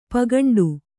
♪ pagaṇḍu